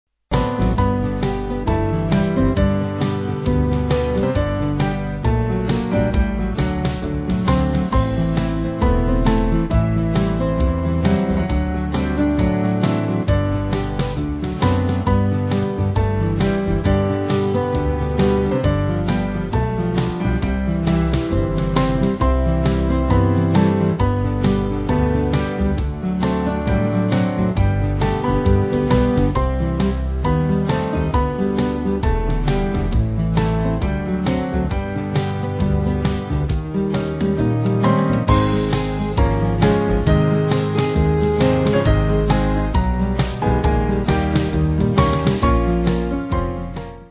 Small Band
(CM)   2/G-Ab